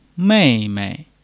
mei4--mei.mp3